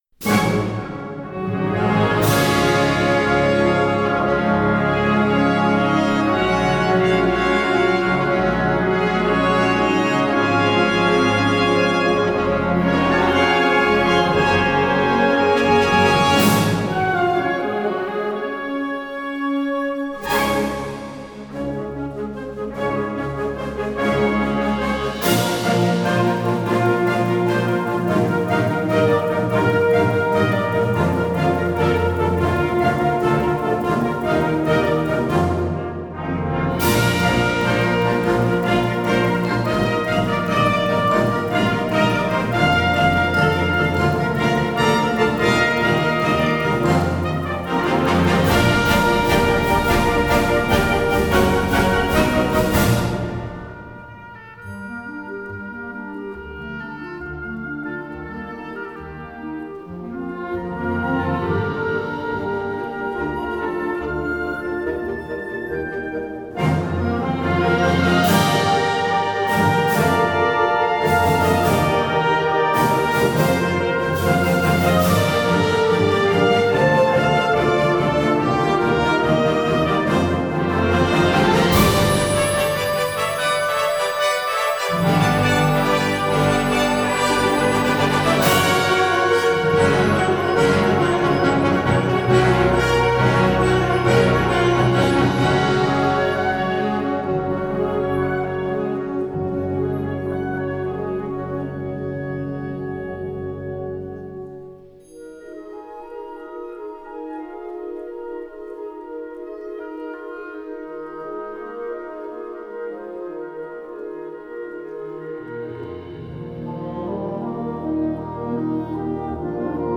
Partitions pour orchestre d'harmonie, ou - fanfare.